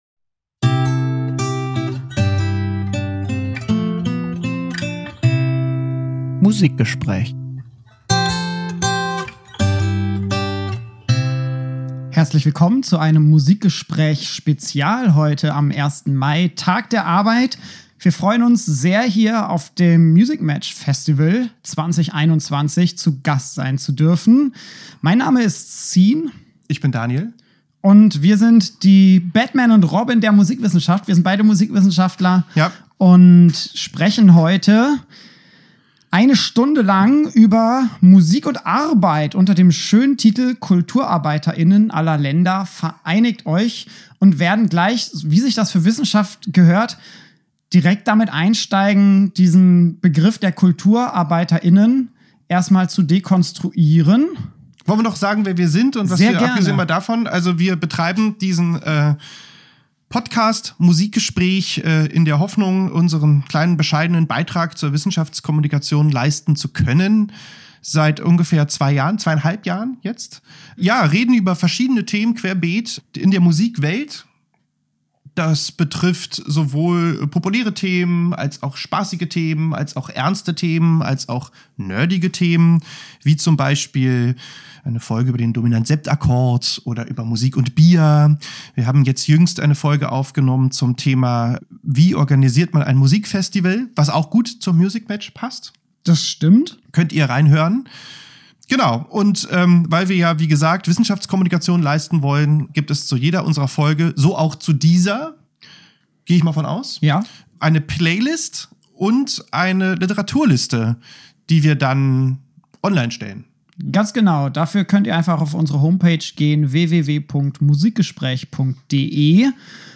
Das Musikgespräch live aufgezeichnet am Tag der Arbeit. Direkt vom MusicMatch Festival 2021 auf eure Ohren: eine Stunde lang sprechen wir über Musik und Arbeit.
musik-und-arbeit-live-beim-musicmatch-2021-mmp.mp3